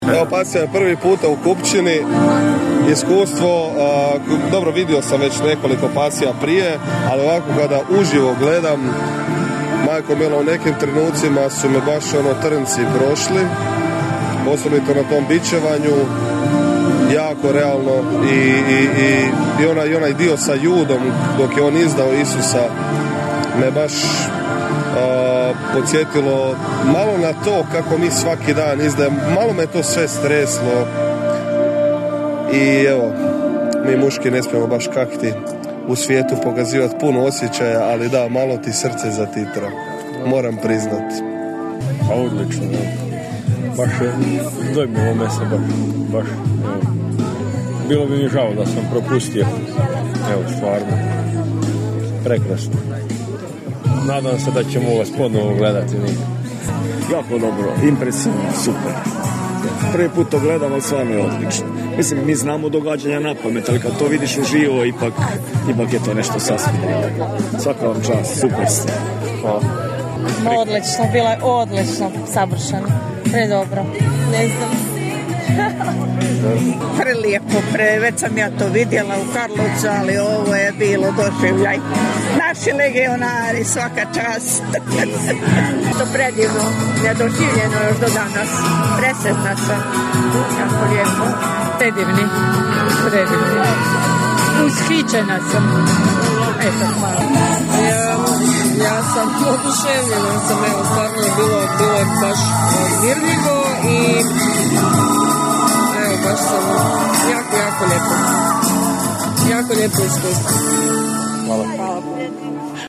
Razgovarali smo i s publikom te snimili dojmove:
publika.mp3